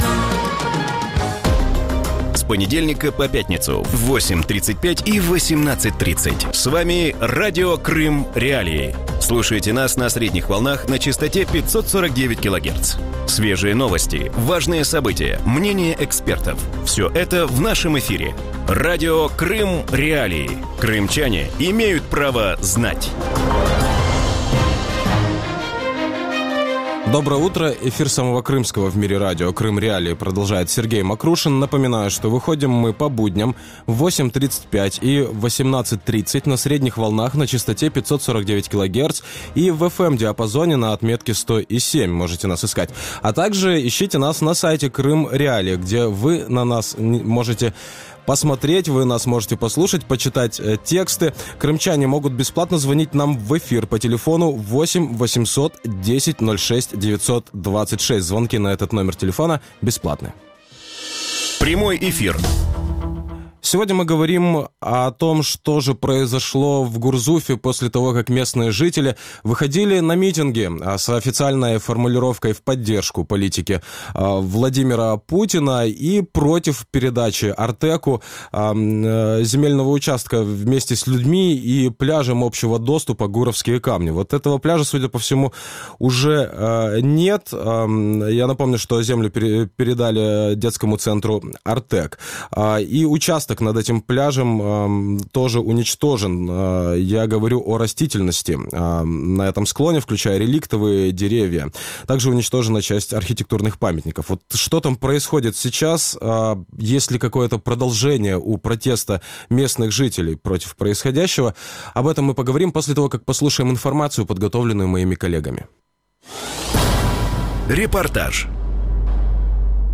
Утром в эфире Радио Крым.Реалии говорят о бывшем пляже «Гуровские камни» в Гурзуфе. Пляжный участок у моря, который был передан детскому центру «Артек», практически уничтожен вместе со всей растительностью на склоне, включая реликтовые деревья и часть архитектурных памятников.